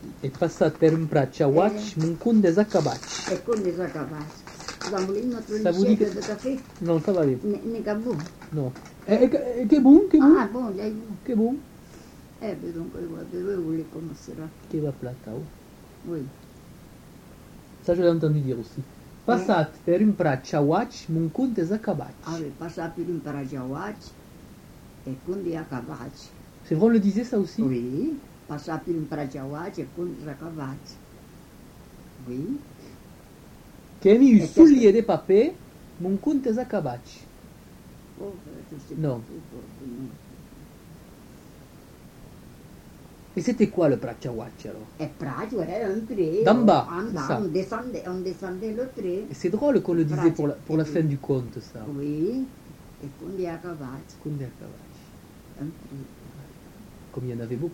Aire culturelle : Couserans
Genre : témoignage thématique